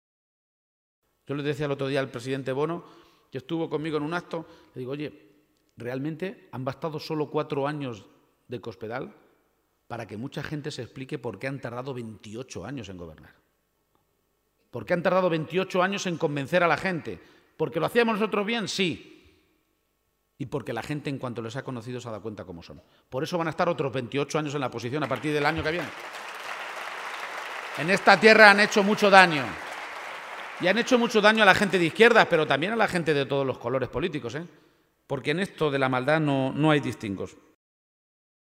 El candidato socialista realizó esta manifestaciones en un acto público ante más de 500 personas celebrado en la Casa de la Cultura de Campillo de Altobuey, localidad de La Manchuela Conquense donde finalizó la visita de Page a esta comarca, tras estar en Motilla del Palancar, Villanueva de la Jara, Minglanilla y La Pesquera, localidades donde se reunió con colectivos y organizaciones sociales.